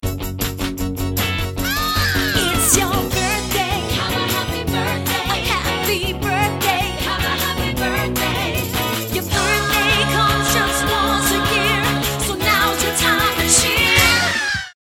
Plays Classic Birthday song.